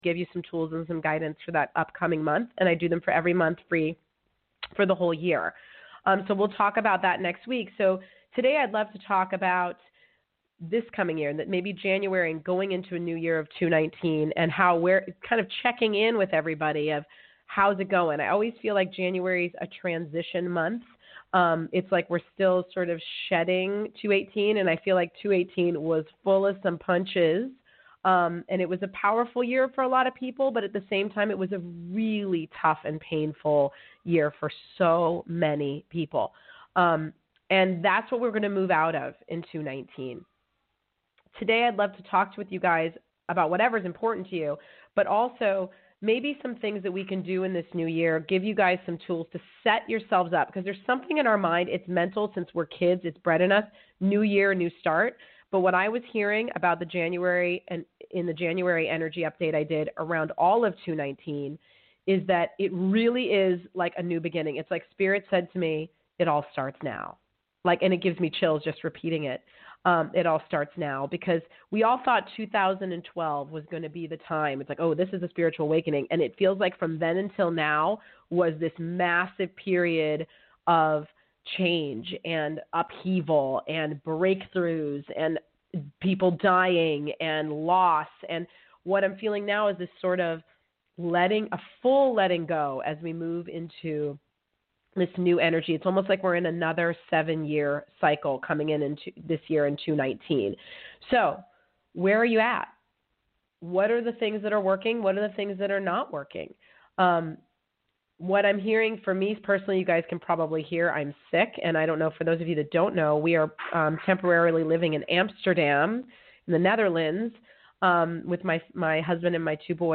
Talk Show Episode, Audio Podcast, Conscious Creation and with Dee Wallace on , show guests , about Dee Wallace,Spiritual Readings,Core Truths,Balanced Life,Energy Shifts,Spiritual Memoir,Healing Words,Consciousness,Self Healing,Teaching Seminars, categorized as Courses & Training,Kids & Family,Paranormal,Philosophy,Motivational,Spiritual,Access Consciousness,Medium & Channeling,Psychic & Intuitive